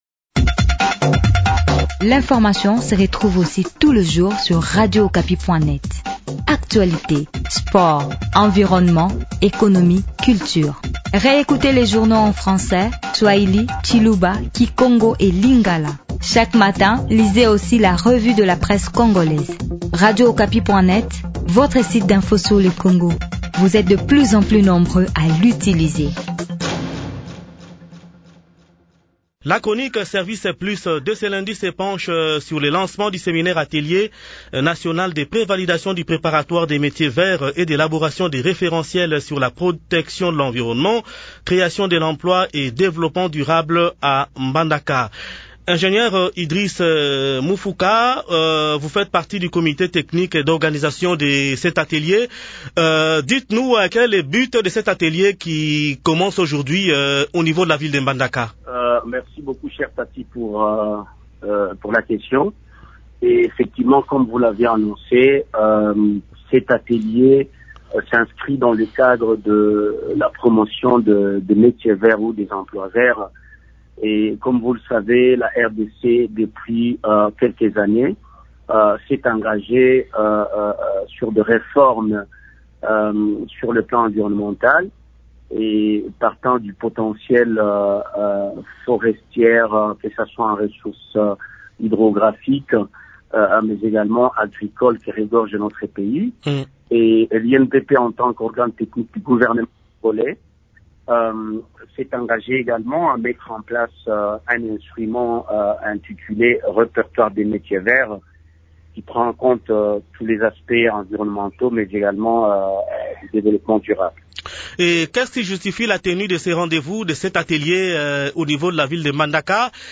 s’entretient sur ce sujet avec l’ingénieur